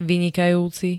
Zvukové nahrávky niektorých slov